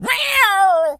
Animal_Impersonations
cat_scream_11.wav